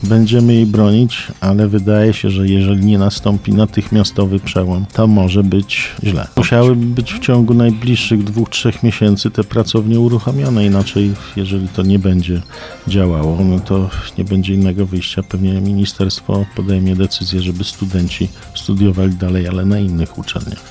– Wciąż nie ma sal do prowadzenia zajęć, dopiero w grudniu został złożony projekt na remont pomieszczeń, które mają być wykorzystywane między innymi na prosektorium – dodaje Suski.